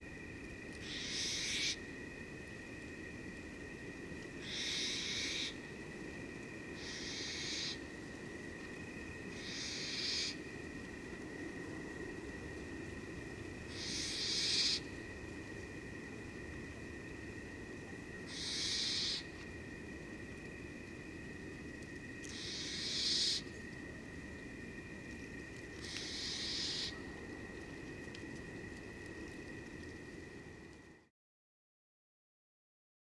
Cape Verde Barn Owl
1-28-Cape-Verde-Barn-Owl-Begging-of-a-juvenile.wav